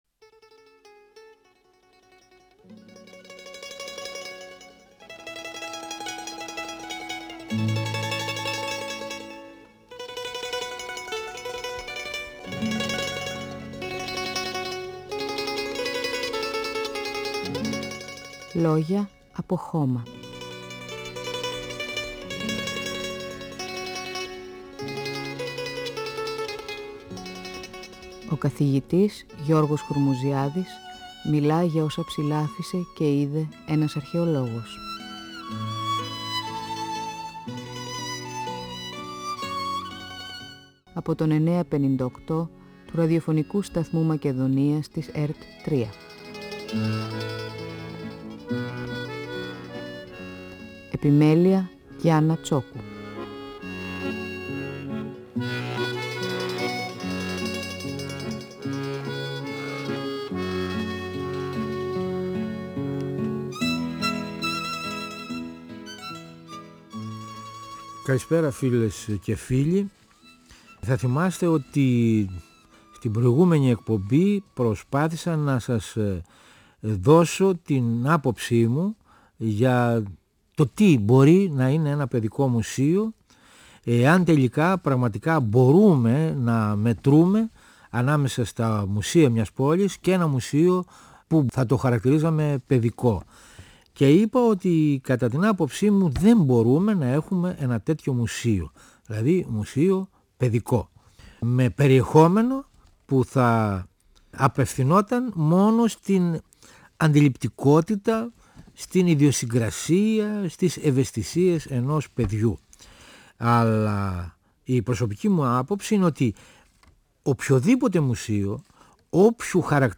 Ο καθηγητής αρχαιολογίας και συγγραφέας Γιώργος Χουρμουζιάδης (1932-2013) μιλά για τον τρόπο έκθεσης σ’ ένα μουσείο και αξιολογεί τα αρχαιολογικά μουσεία. Αναφέρεται στο πώς σε άλλες χώρες της Ευρώπης μεγάλα μουσεία προσαρμόζουν τη λειτουργία τους στα ενδιαφέροντα των παιδιών. Παρουσιάζει τα ιδιαίτερα χαρακτηριστικά, τον ρόλο και τη θέση ενός μουσείου ιδιωτικής συλλογής.